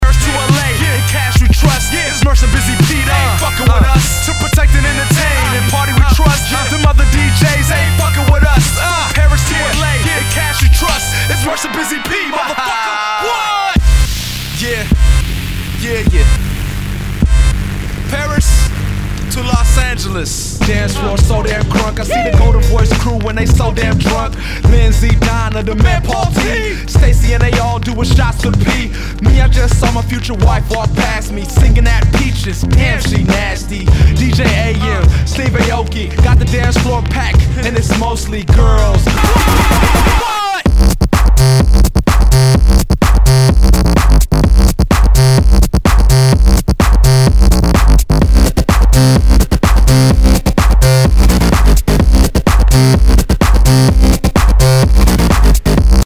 HIPHOP/R&B
ナイス！エレクトロ・ヒップホップ / ハウス！
全体にチリノイズが入ります